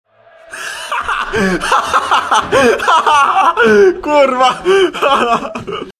Śmiech